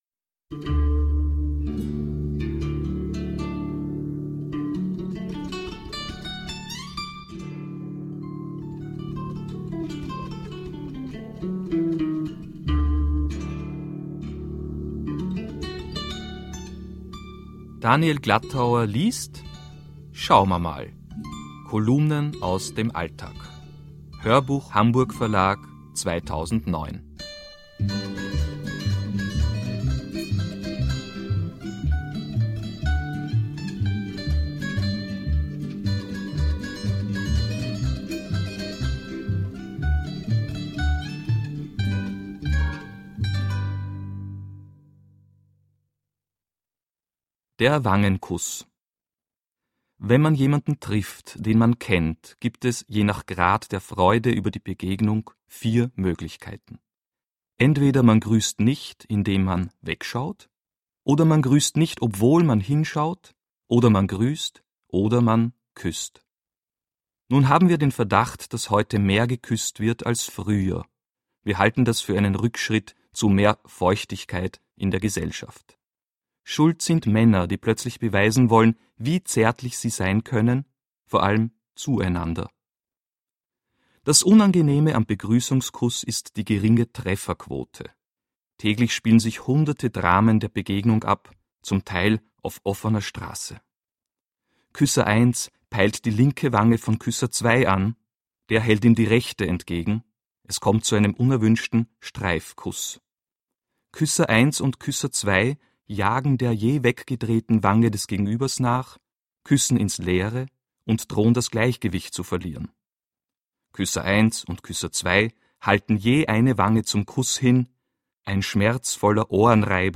Schauma mal - Daniel Glattauer - Hörbuch